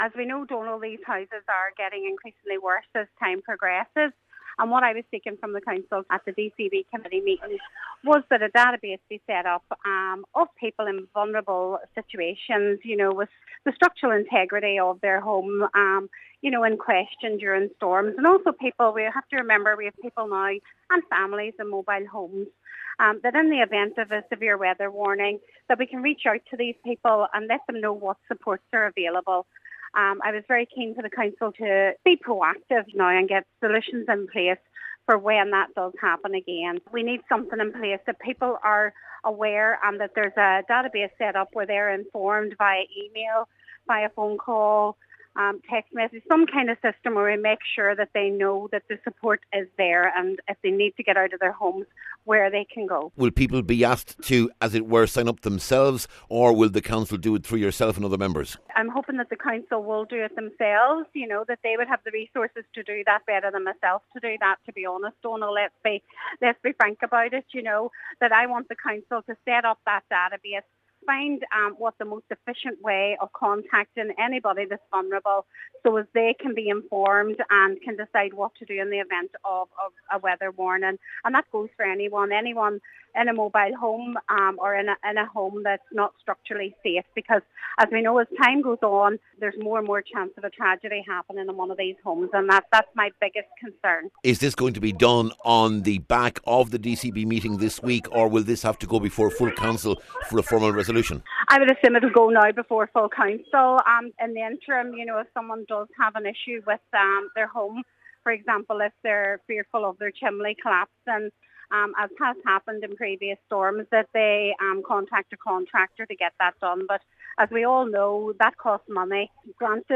Cllr Beard says now is the time to put the groundwork in place…………..